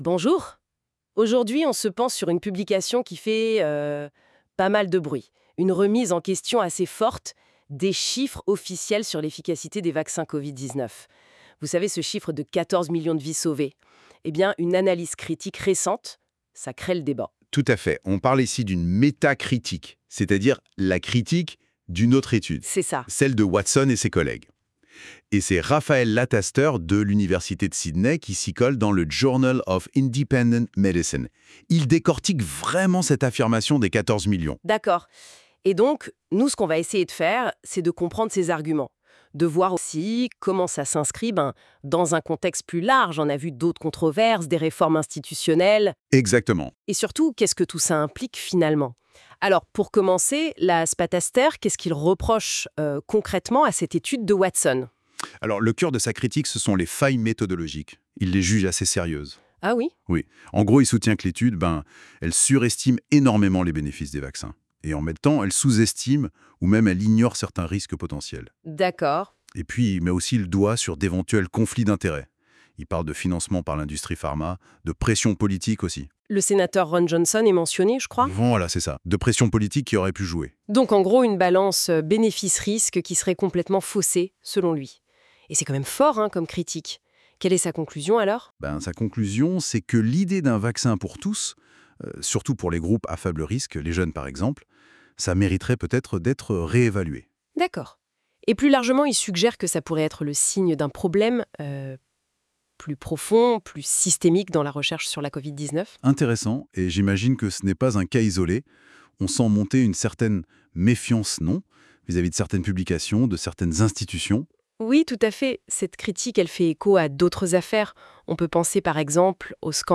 Lire la suite de l’article de FranceSoir ici : Vous trouverez en fichier joint au présent article un résumé de la metacritique par NotebookLM Vous y trouverez également un podcast audio en français, toujours de NotebookLM, à propos de cette metacritique.